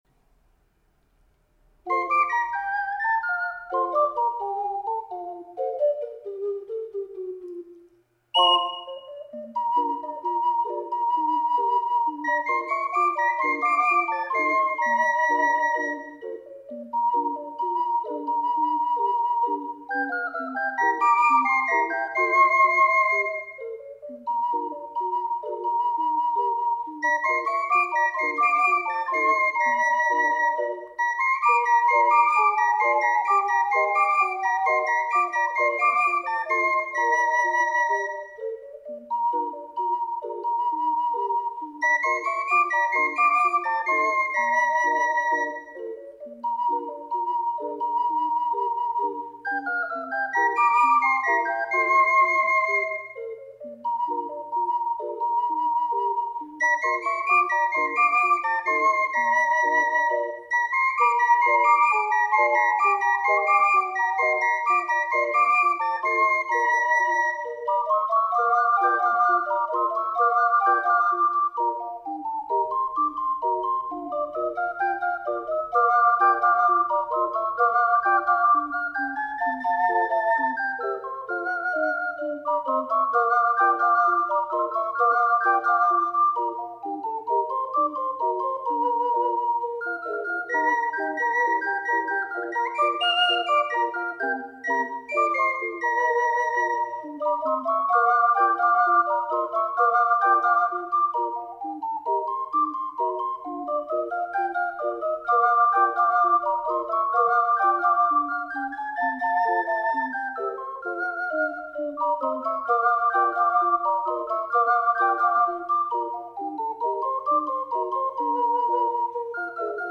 私はコントラ管を持っていないので、1st～6thの六重奏にしてみました。